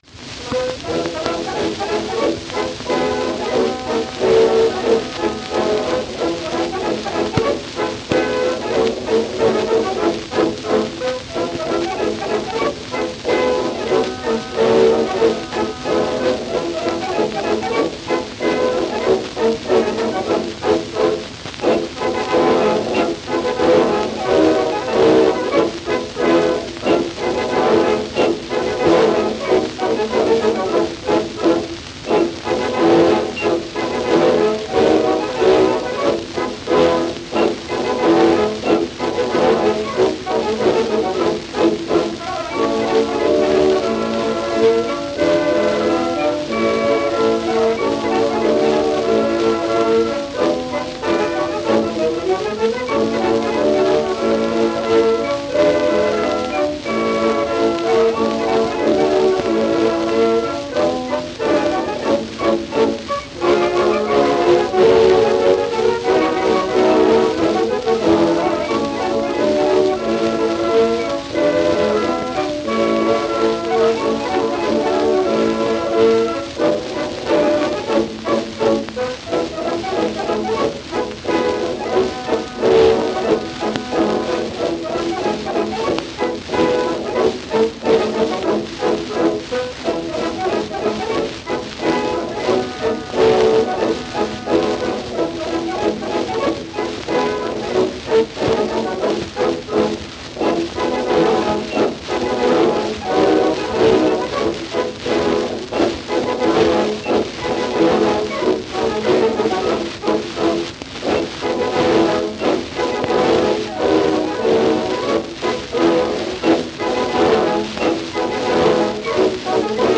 A w wersji orkiestrowej tak (źródło):
W każdej brzmi niemiecko i faktycznie –  jak podaje internet (źródła: 1, 2, 3), jego melodia ma być napisana w 1892 przez Franza Meiẞnera.